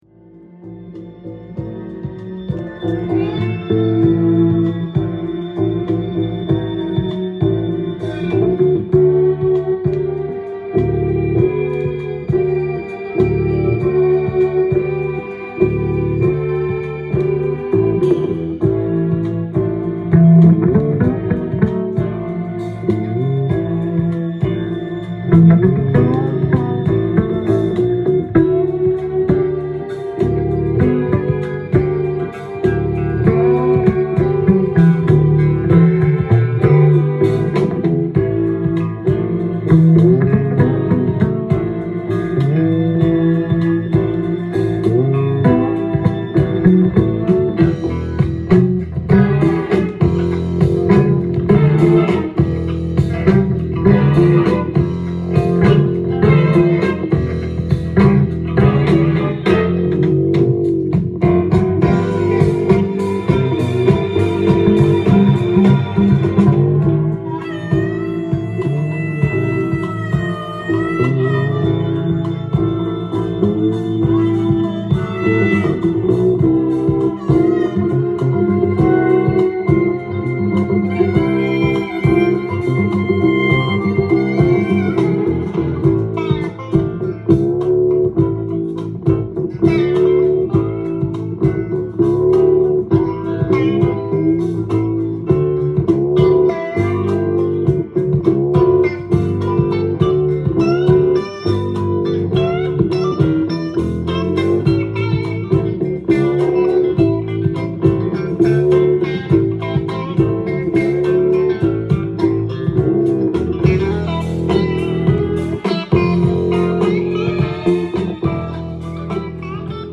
店頭で録音した音源の為、多少の外部音や音質の悪さはございますが、サンプルとしてご視聴ください。
グルーヴ感満点のベースを聴かせる1枚！